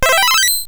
egg_hatch.wav